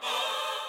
SouthSide Chant (55).wav